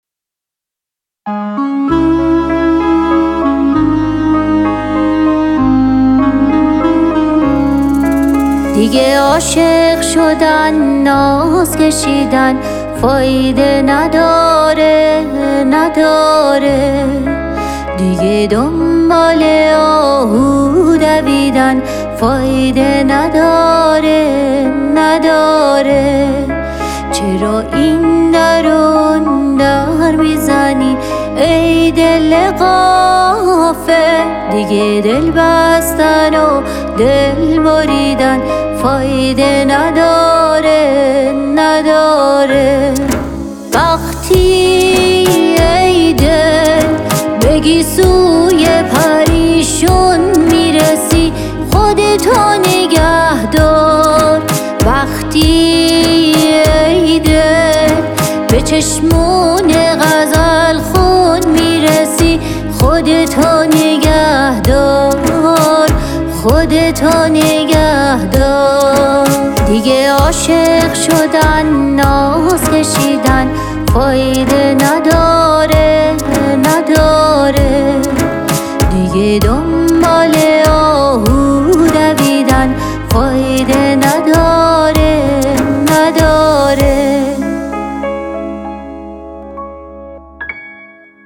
پاپ
بازخوانی